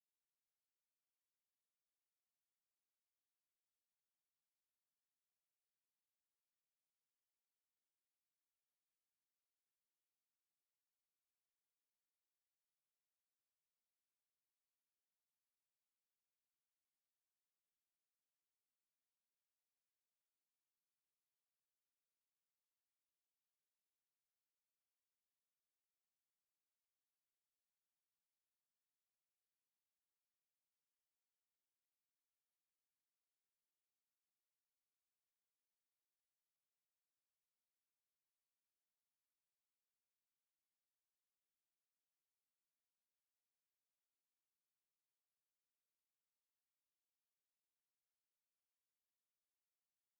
Conversation with MCGEORGE BUNDY, October 27, 1964
Secret White House Tapes